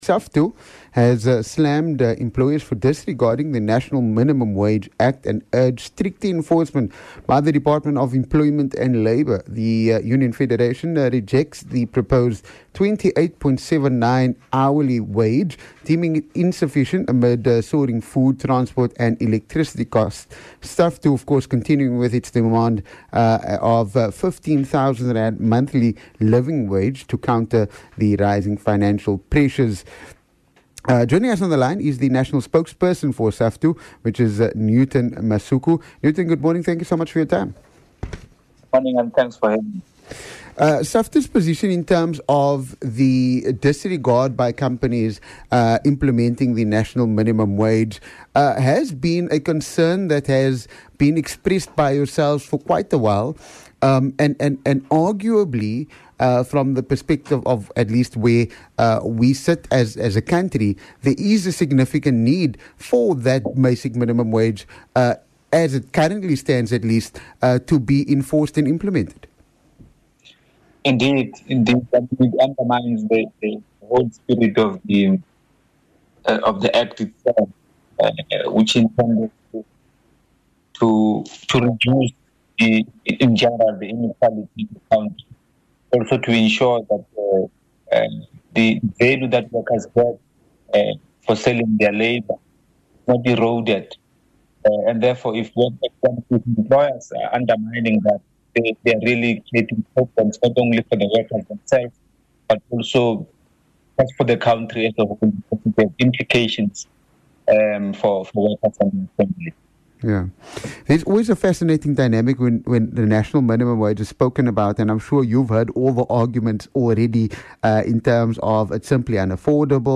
SAFTU-interview.mp3